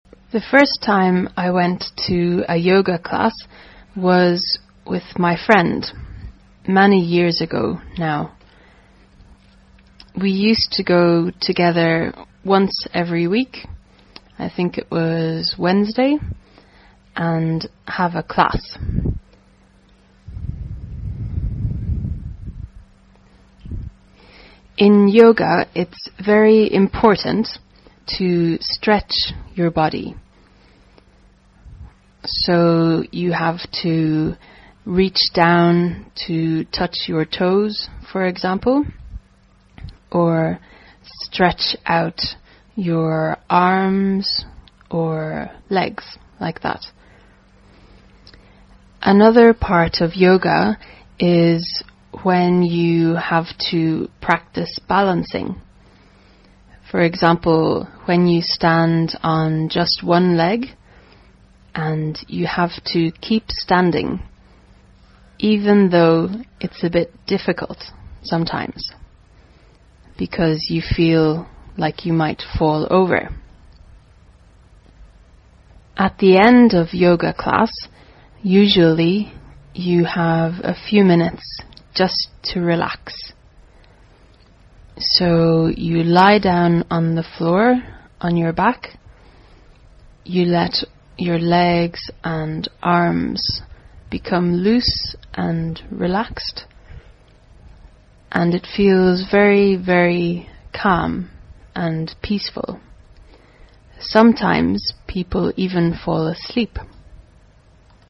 实战口语情景对话：Yoga 瑜伽